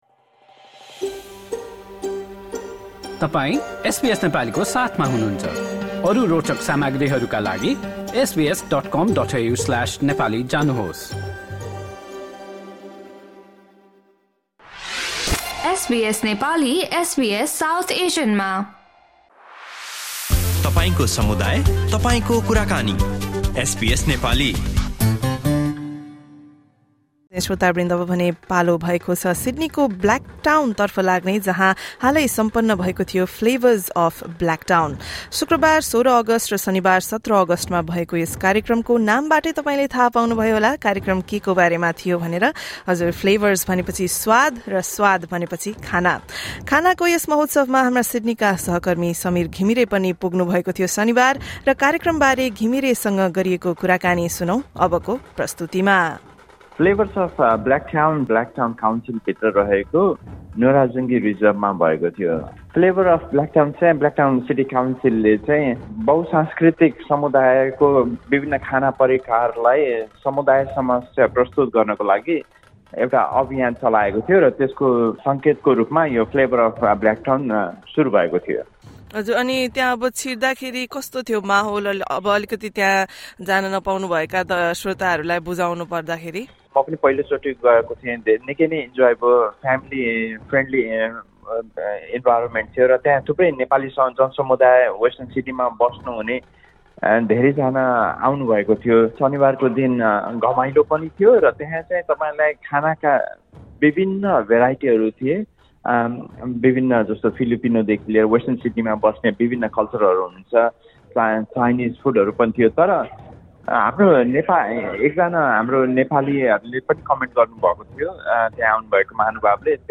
Members of the Nepali community present at the event on Saturday spoke to SBS Nepali.